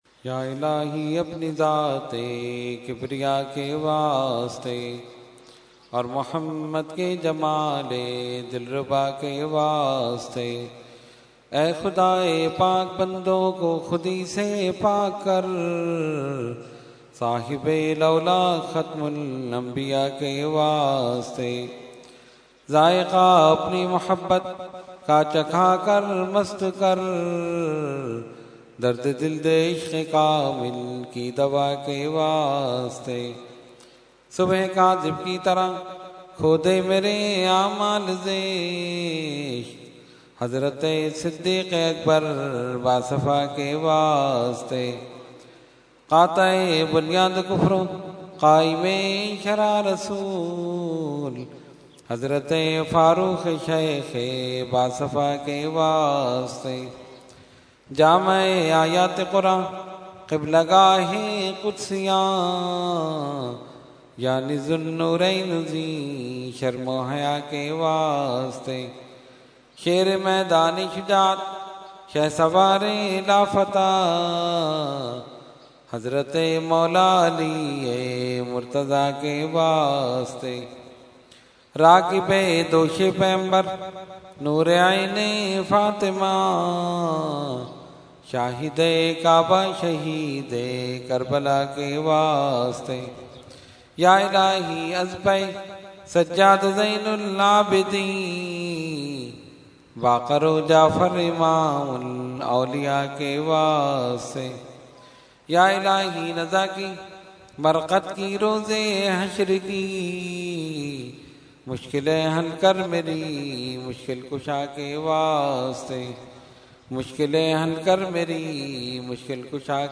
Dua – Shabe Meraj 2015 – Dargah Alia Ashrafia Karachi Pakistan
Category : Dua | Language : UrduEvent : Shab e Meraaj 2015